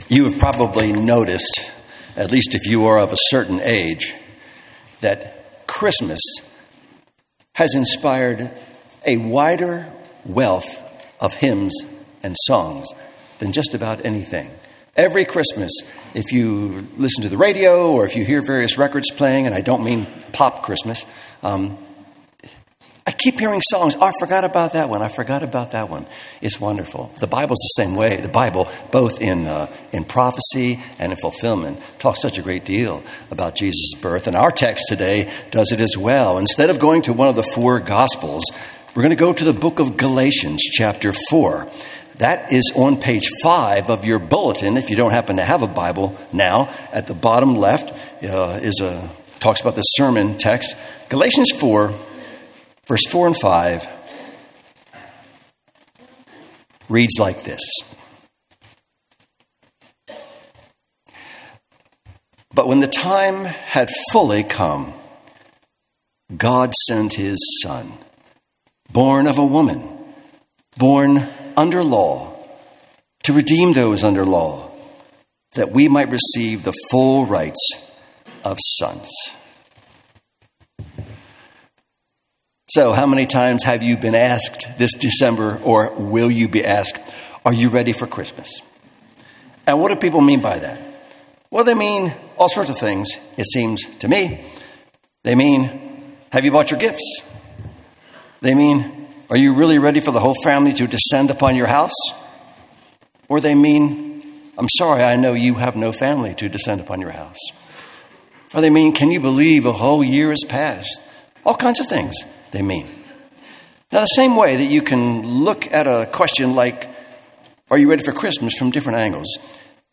Sermon Outline 1.